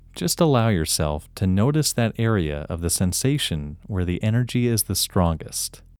IN – First Way – English Male 5